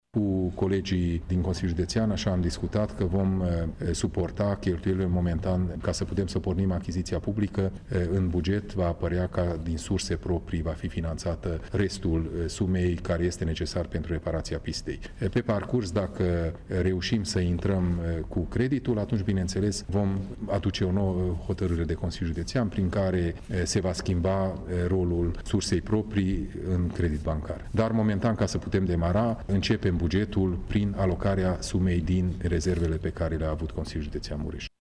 În aceste condiții, sumele necesare efectuării lucrărilor la Aeroportul Transilvania vor fi alocate din rezervele Consiliului Județean, spune președintele instituției, Peter Ferenc: